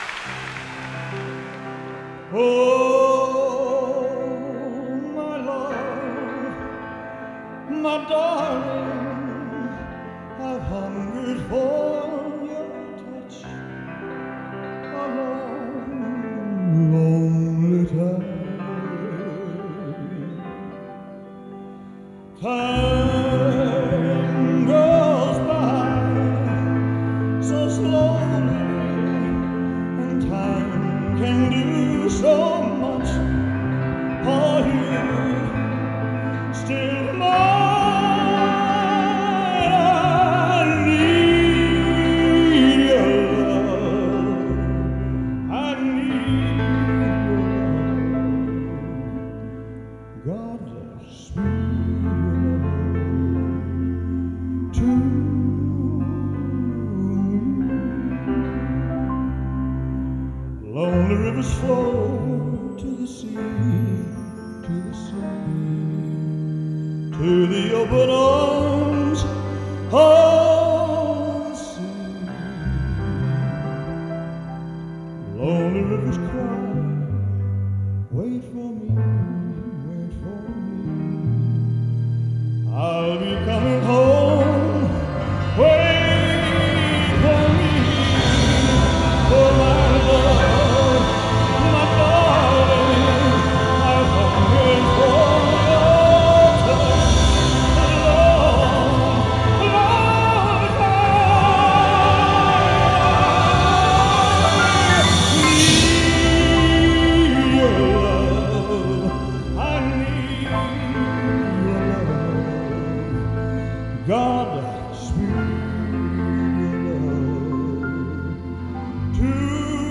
Рок-н-ролл